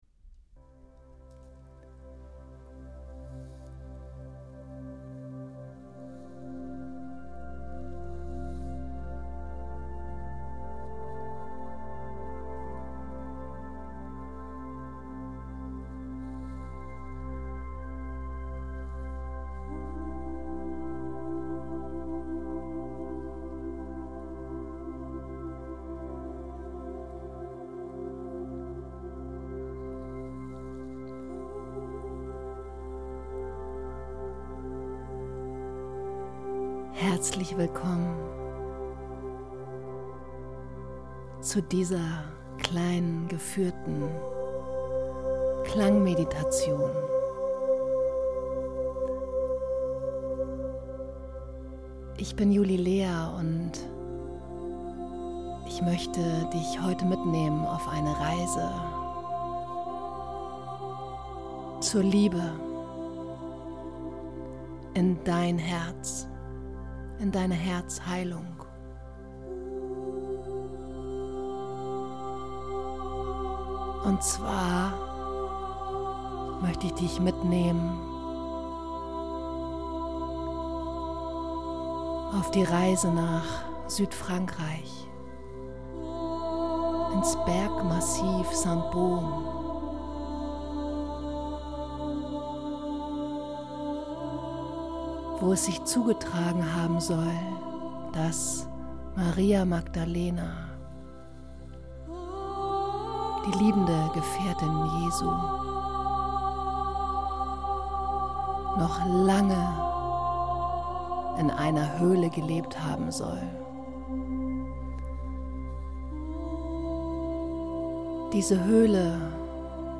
Klangreise starten Suche Dir einen ruhigen Ort an dem Du ungestört bist und folge dem Klang.